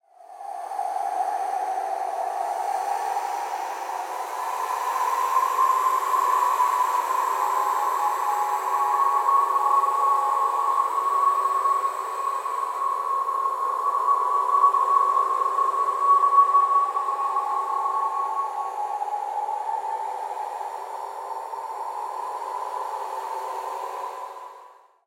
zapsplat_science_fiction_mysterious_cold_wind_planet_19268
Tags: ghost